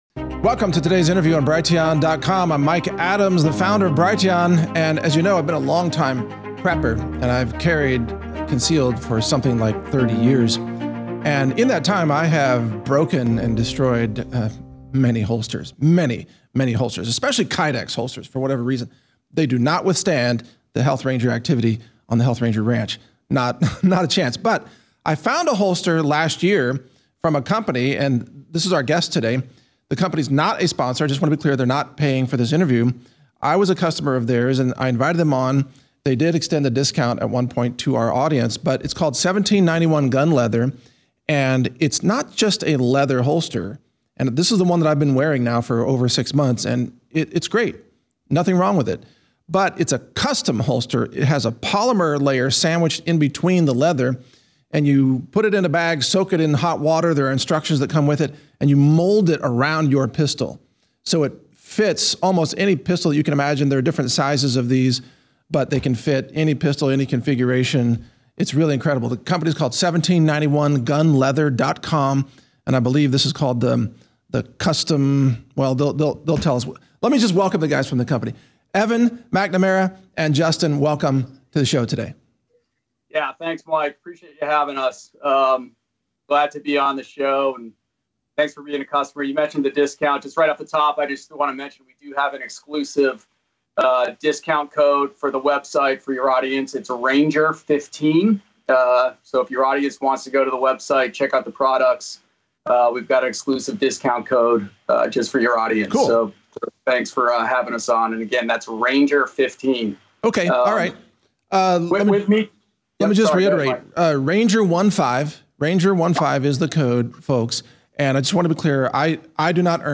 RELIABLE GEAR: An interview with 1791 Gun Leather about their moldable gun holsters that you can reshape to fit almost any pistol - Natural News Radio